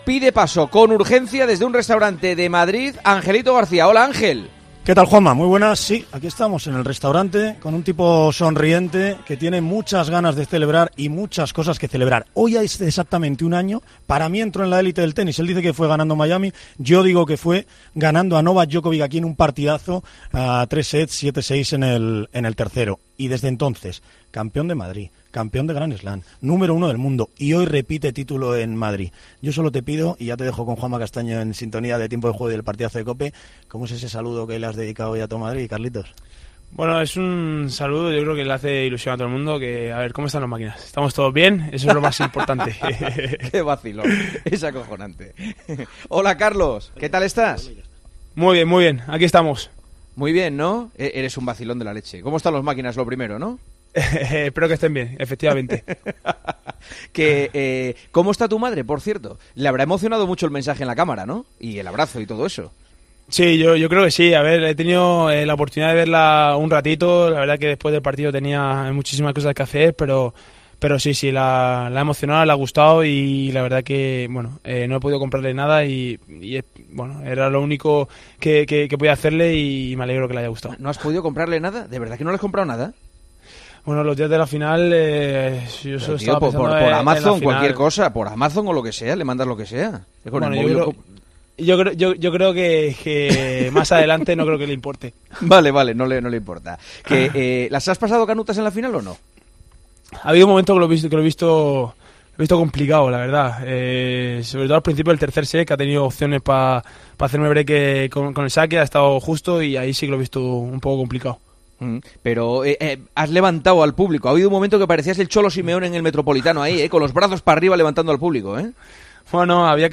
El murciano conquistaba el cuarto Másters 1.000 de su carrera a los 20 años y pasaba por los micrófonos de Tiempo de Juego . Ha confirmado que su intención es acudir a Roma y ha explicado el por qué del mensaje a su madre en la cámara tras la victoria.